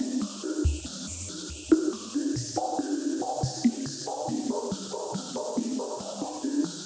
STK_MovingNoiseD-140_02.wav